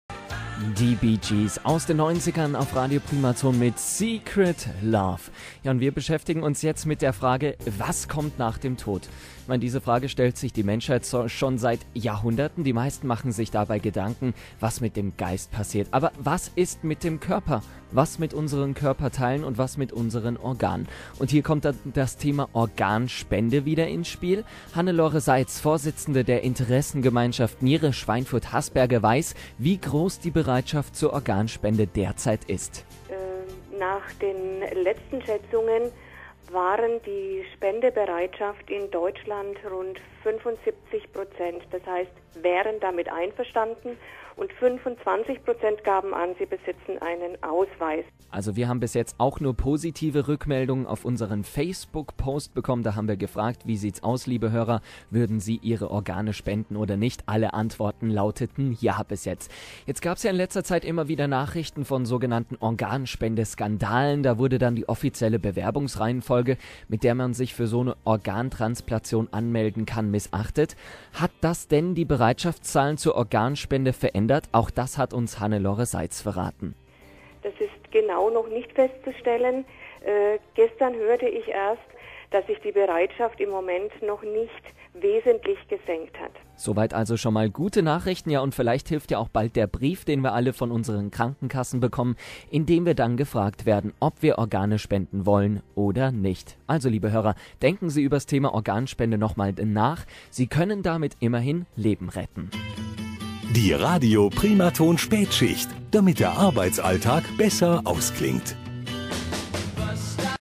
Interview zum Thema "Was kommt nach dem Tod"